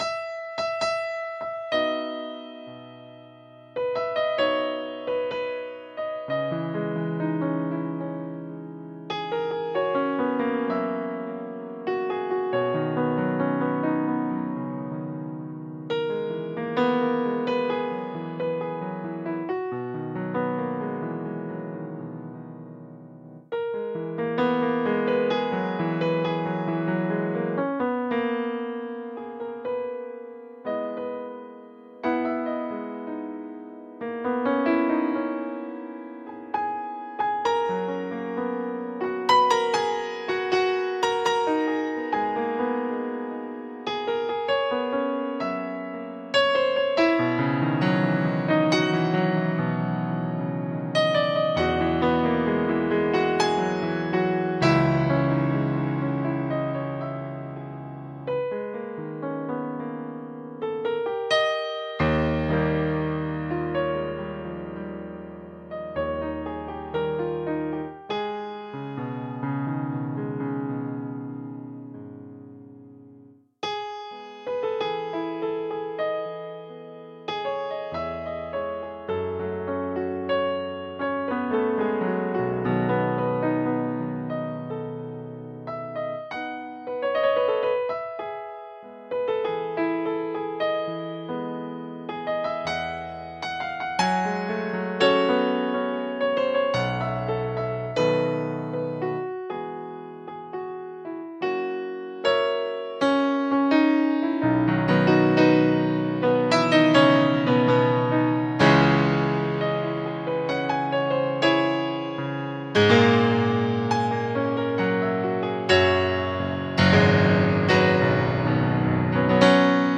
Фортепиано
Пьеса содержит фрагмент, который стал первой написанной мной мелодией для фортепианного произведения. 2017 г. Shadows of spring Темы этого произведения для меня почему-то ассоциируются с весенним настроением. Эмоциональная окраска мелодий в нём очень проста и почти не скрывает никаких тайных смыслов.